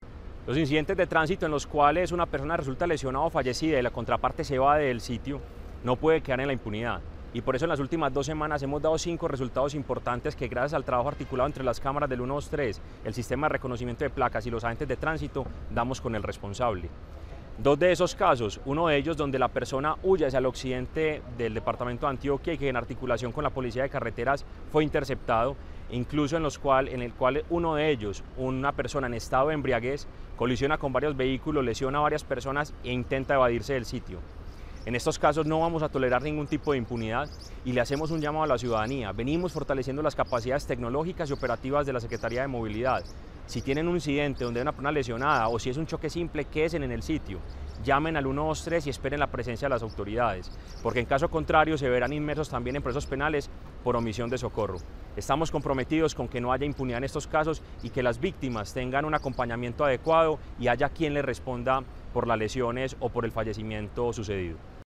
Palabras de Mateo González Benítez, secretario de Movilidad